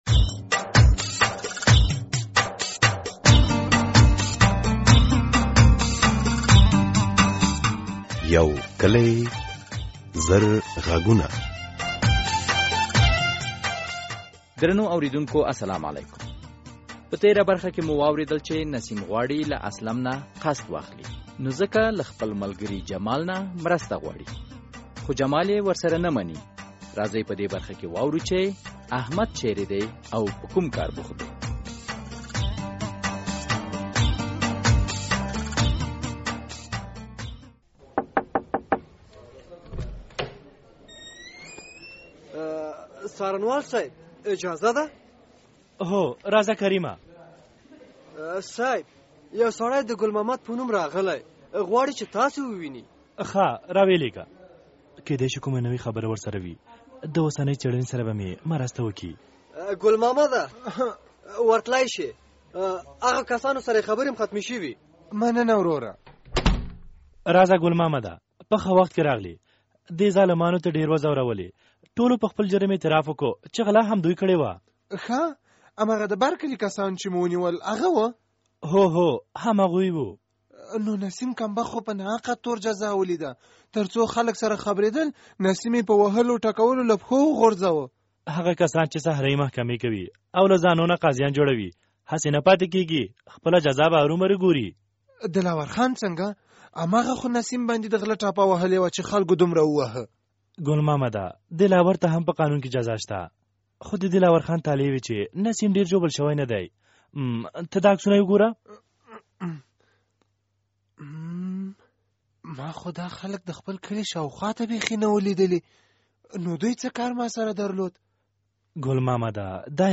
دا ډرامه چې تر ډيره په کې د کورنیو، ښځو او ځوانانو حقوقي مسایل بیانیږي هره (دوشنبه) د مازدیګر په ۴:۳۰ بجو د ازادي راډيو له څپو ۱۰۰.۰۵ اف ام او منځنی څپو ۱۲۹۶ خپریږي.